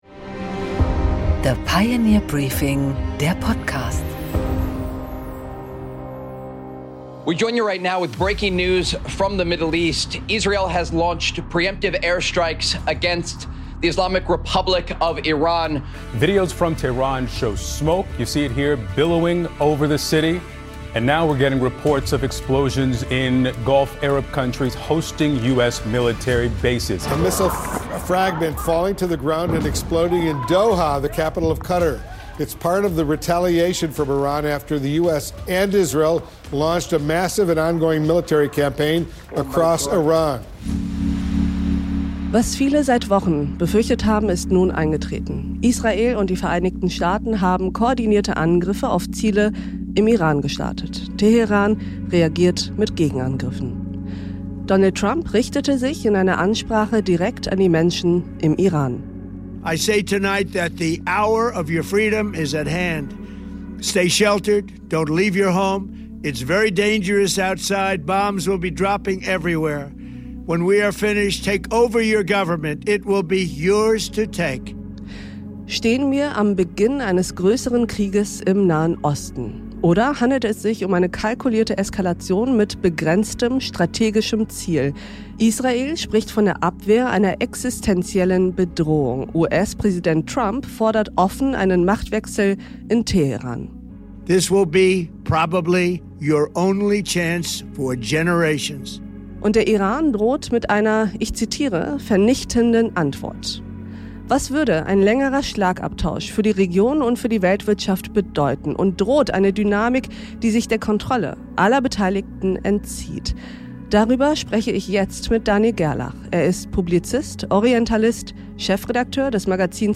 Ein Gespräch über Eskalationsrisiken, politische Illusionen – und die Zukunft einer ganzen Region.